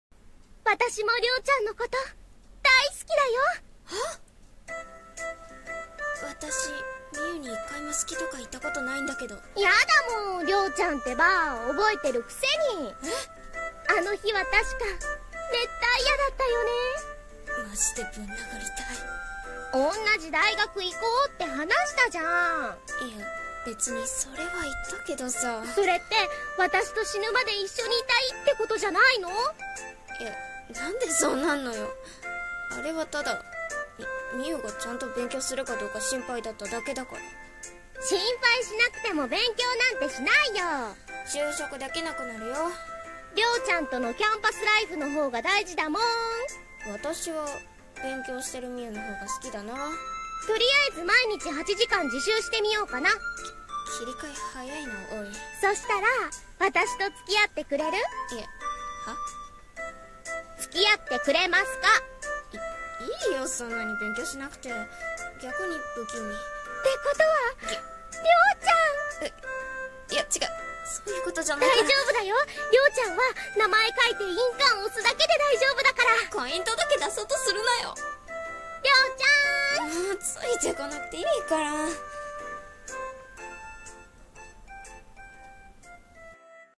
【コラボ声劇】スキスキりょーちゃん